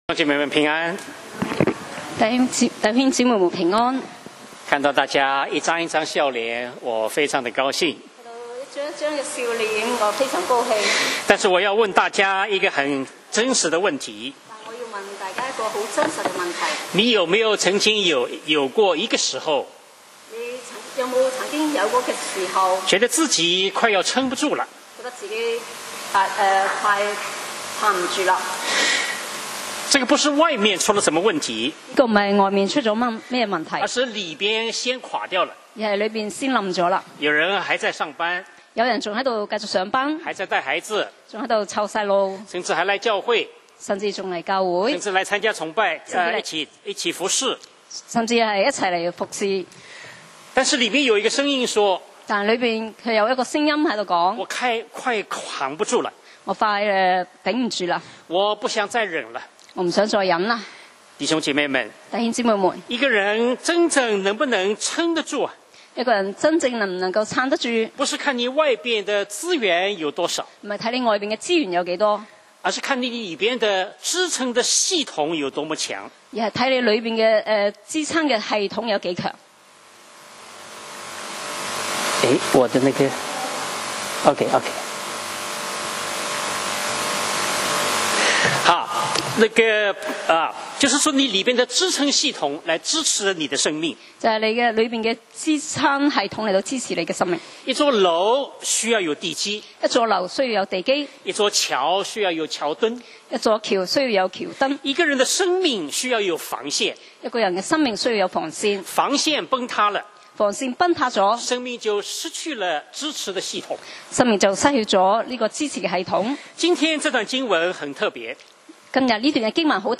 講道 Sermon 題目：软弱与得胜系列四：从反叛到尊重 經文 Verses：林前4：1-14。